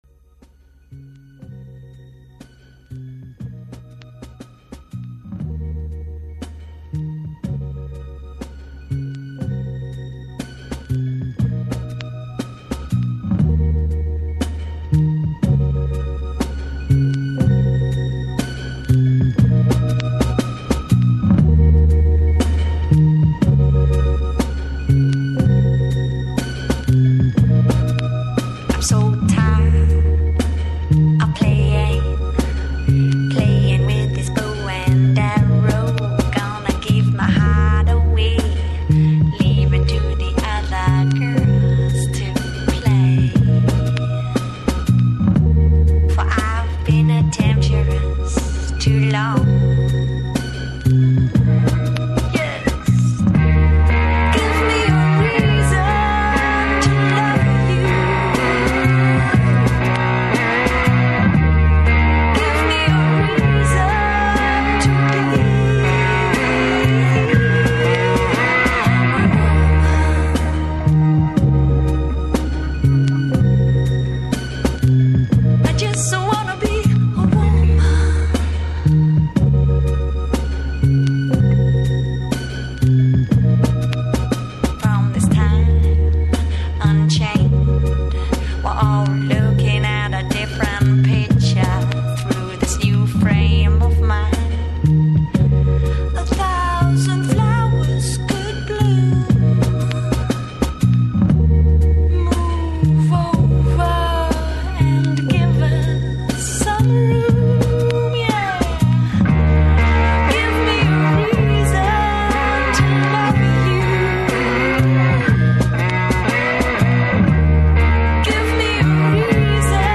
Гост емисије је Јован Ћирилов, уметнички директор и селектор БИТЕФ-а, који ће ове године бити одржан 45. пут.